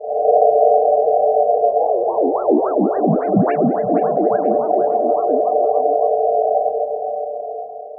Session 14 - Spooky Pad 02.wav